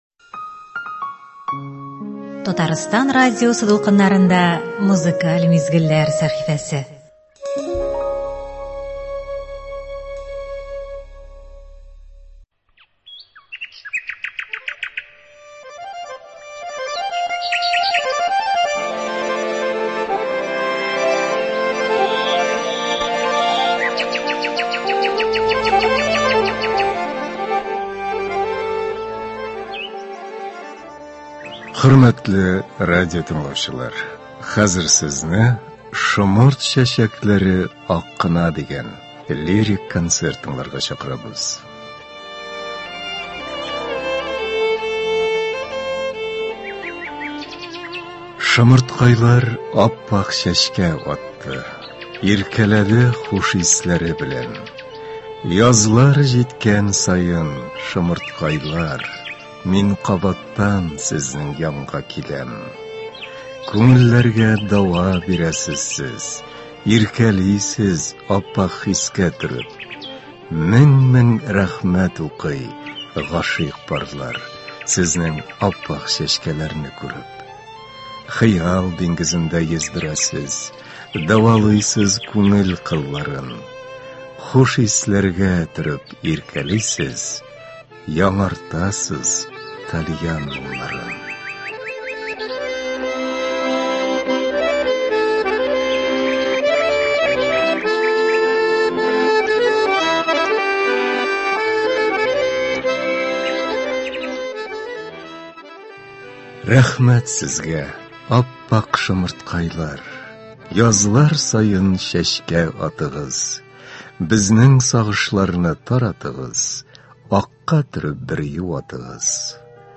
Музыкаль мизгелләр – бу иртәдә безнең радио сезгә көн буена яхшы кәеф бирә торган җырлар тәкъдим итә.